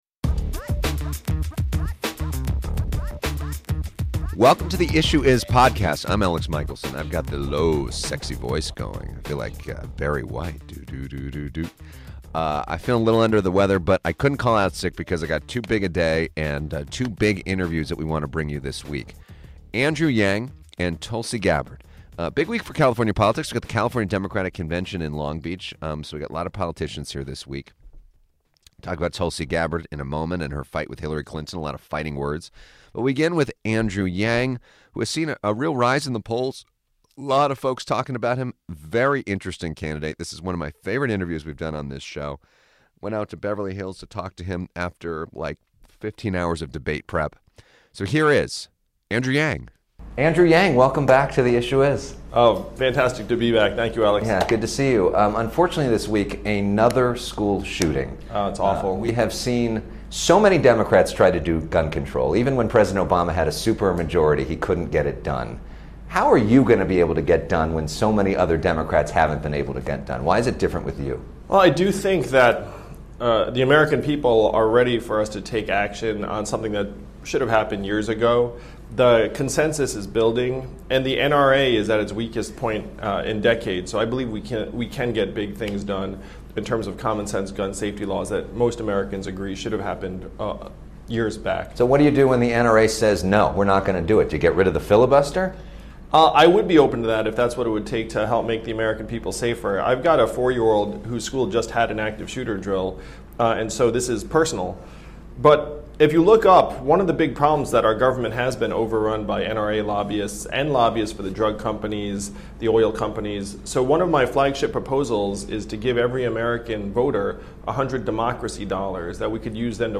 This week, we have two, exclusive one on one interviews with two Democratic presidential candidates.